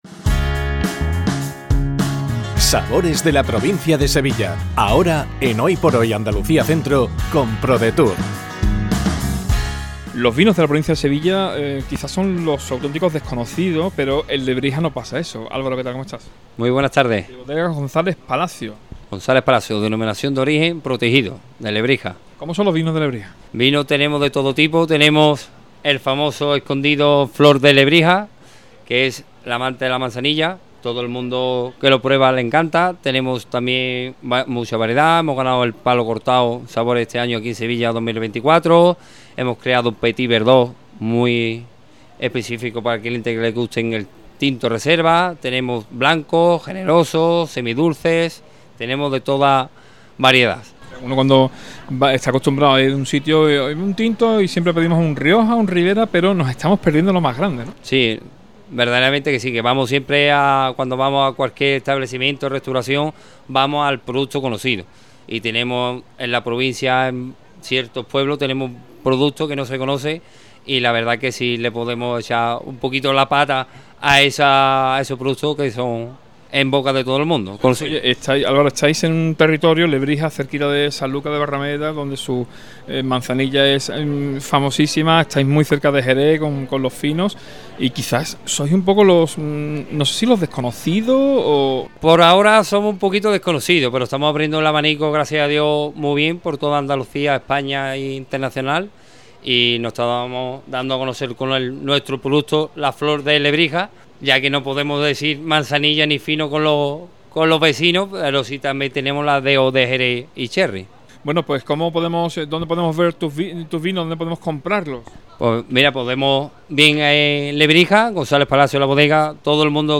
ENTREVISTA | BODEGAS GONZALEZ PALACIOS